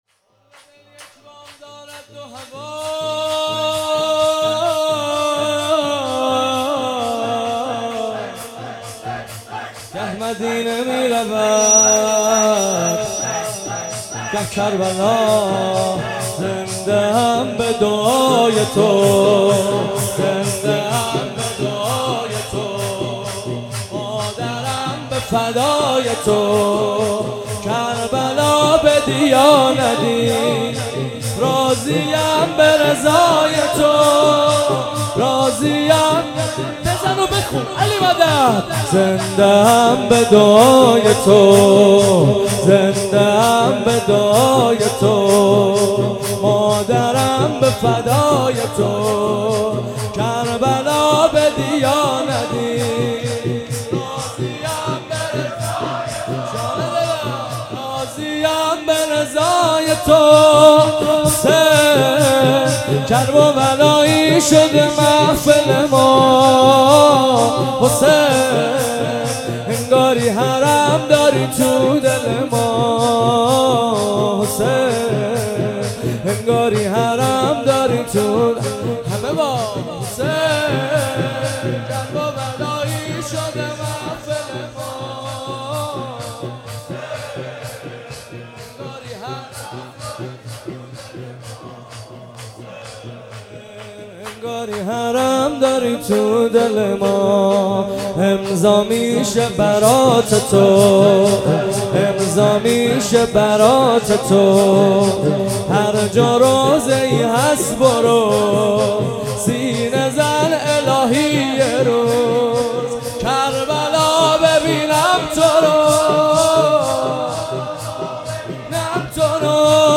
مراسم شب ۲۸ محرم ۱۳۹۷
دانلود شور